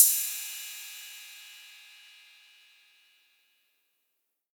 808CY_7_Tape_ST.wav